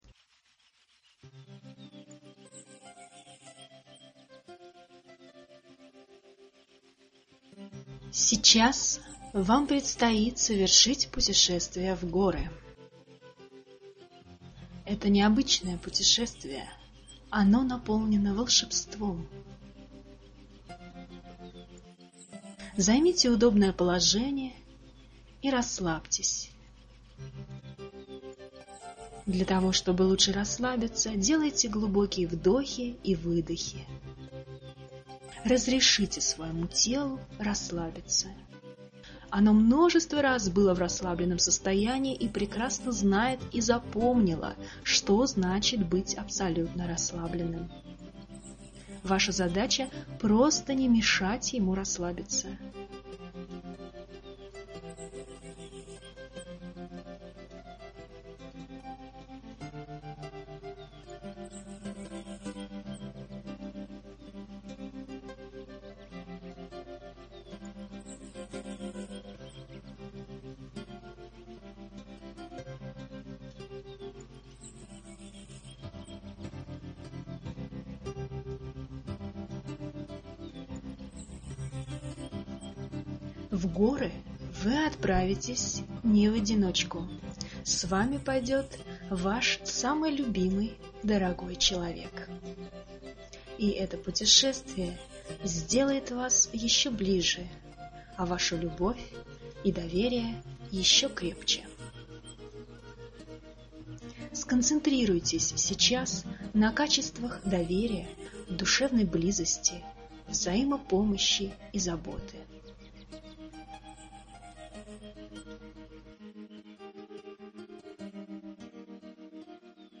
ПУТЕШЕСТВИЕ В ГОРЫ (медитация)
Выберите звуковое сопровождение: с музыкой или звуками природы.